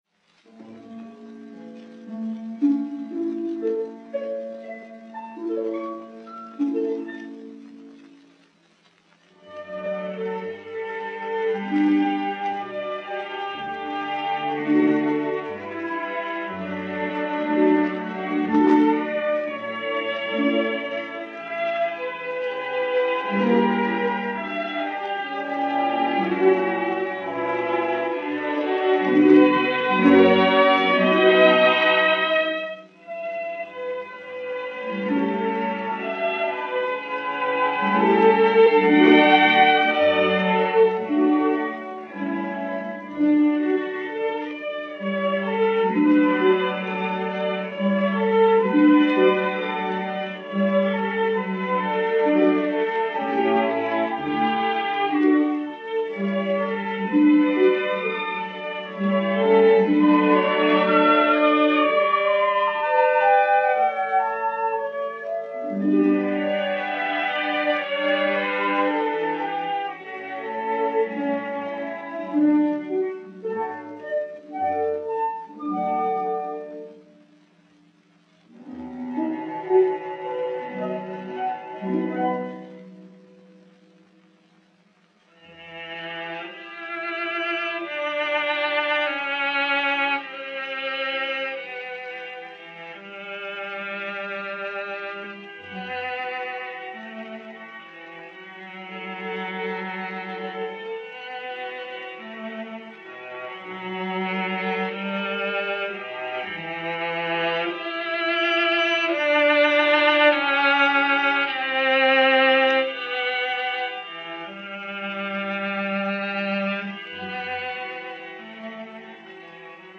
Puis vient une scène religieuse avec chœurs, dont les lignes d'une grandeur sobre évoquent le souvenir de Gluck, quoique l'ensemble reste d'une grande originalité sous son aspect classique.
Maurice Maréchal (violoncelle) et Orchestre Symphonique dir.